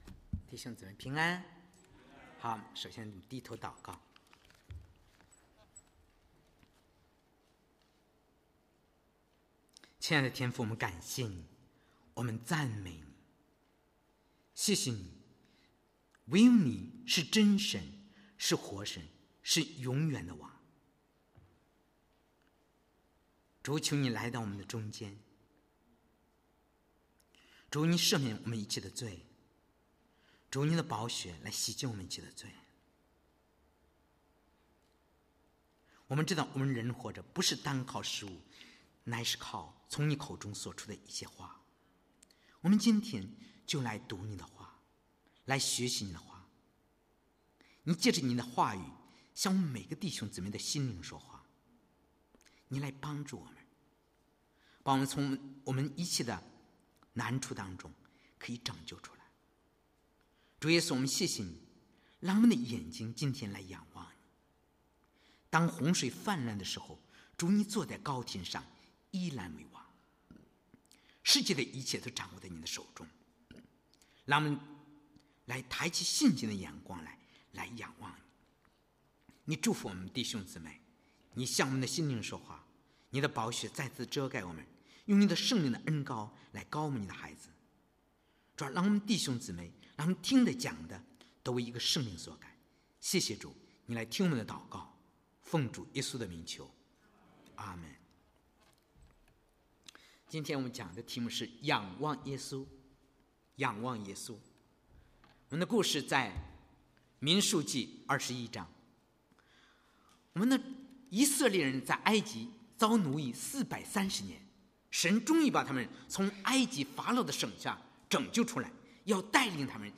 Narwee Baptist Church - Sermon Archive